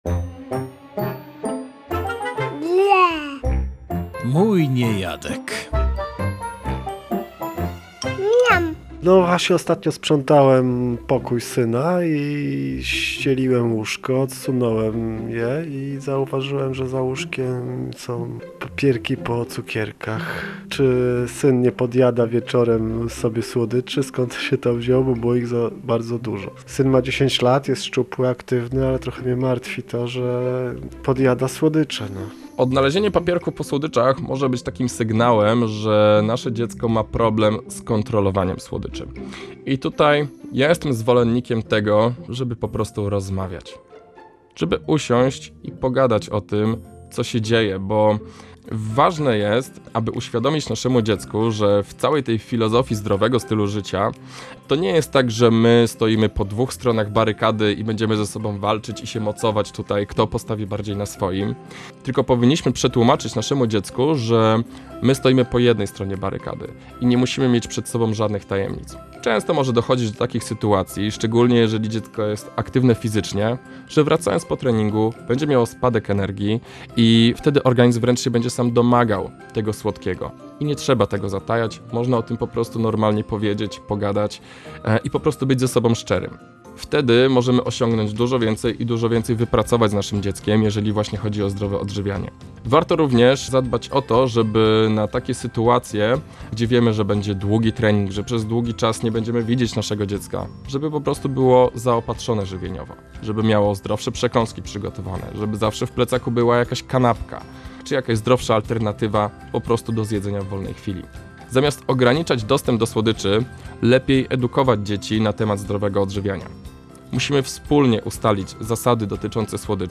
Dietetyk podpowiada, jak poradzić sobie z problemem podjadania słodyczy przez dziecko - Radio Gdańsk